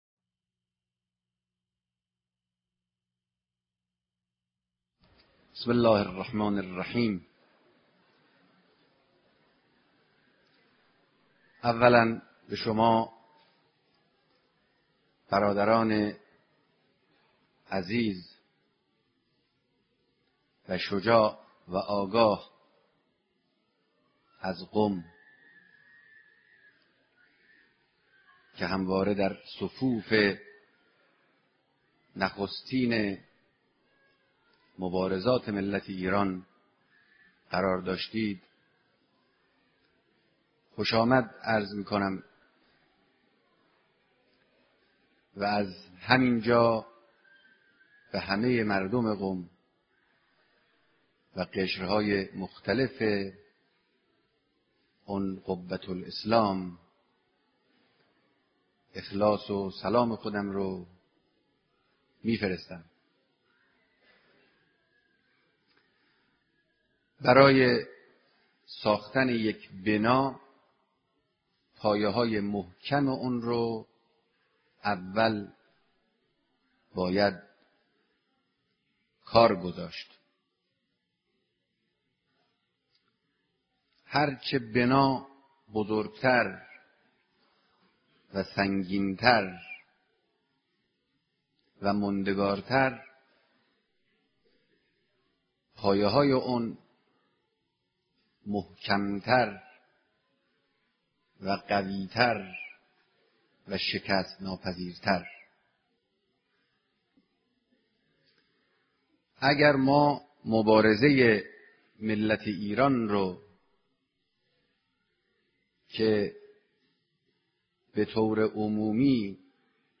سخنرانی در دیدار با جمع کثیری از اقشار مختلف مردم قم، بهمناسبت فرا رسیدن 19 دی